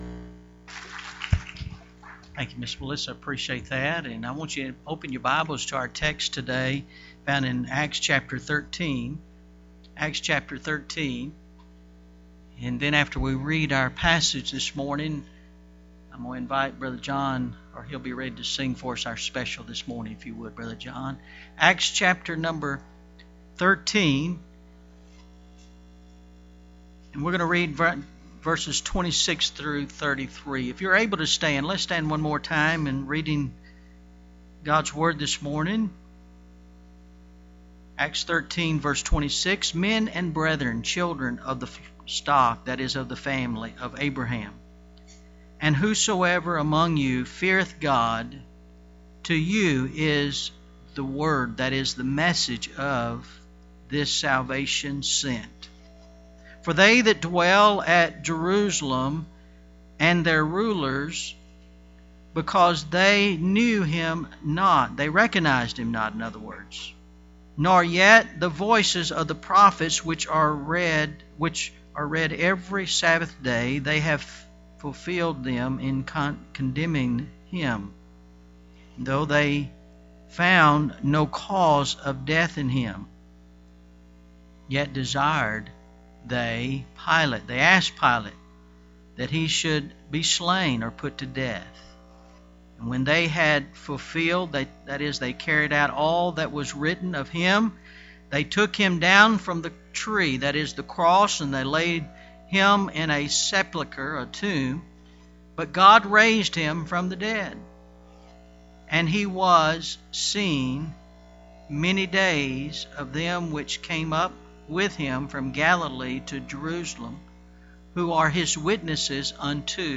Declaring The Gospel – November 2nd, 2014 – AM Service